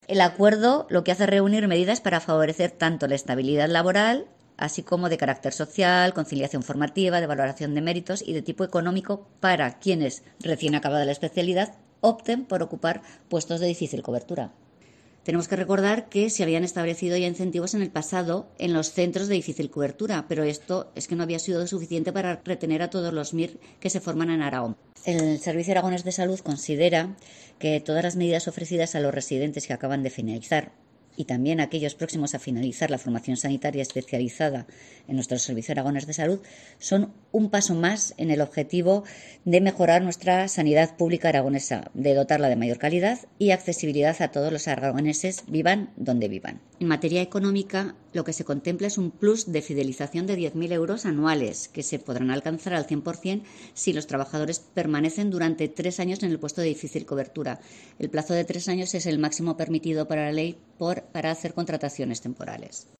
La gerente del Salud, Ana Castillo, explica los incentivos aprobados para fidelizar a los MIR